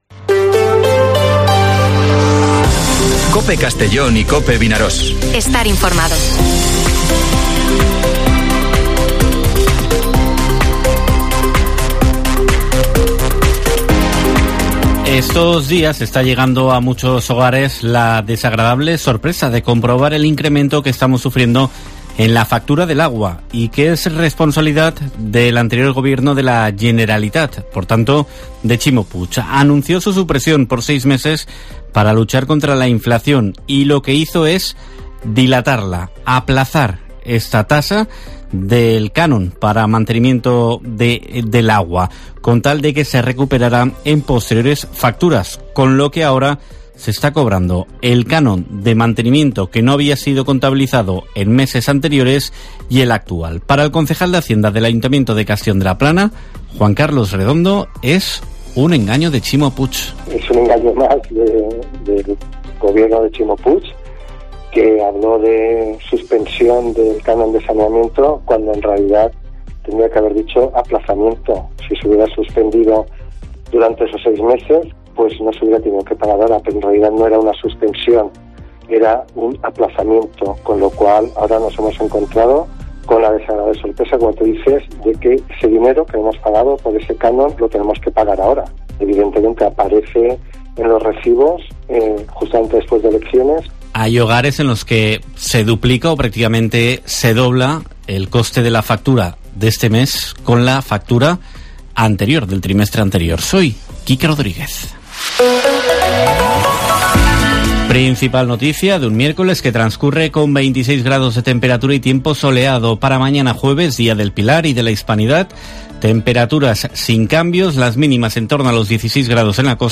Informativo Mediodía COPE en Castellón (11/10/2023)